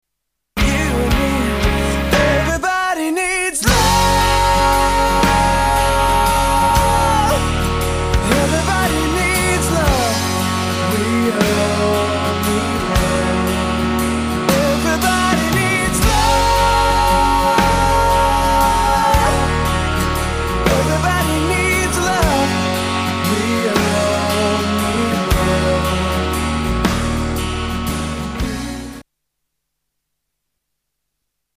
STYLE: Rock
It does sound sort of '80s retro...